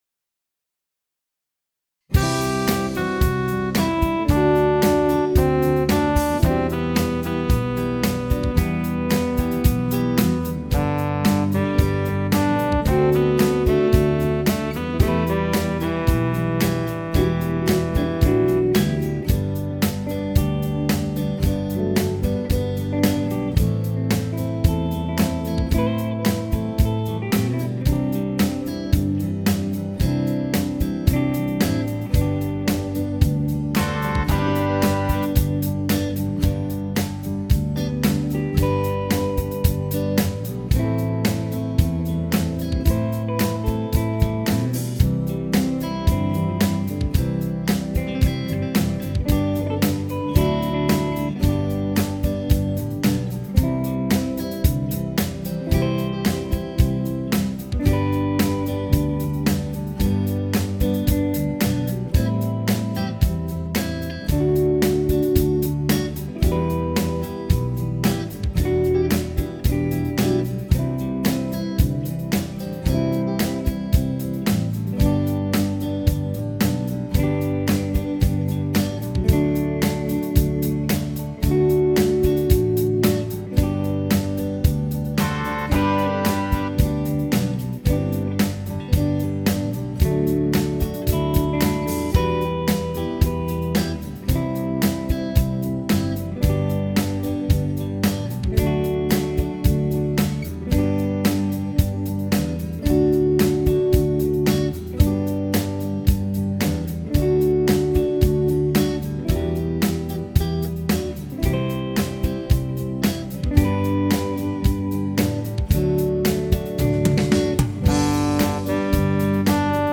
鼻笛練習用音楽素材
鼻笛教室等で使用の練習用素材はこちらにまとめます。
トンボ(slow)　伴奏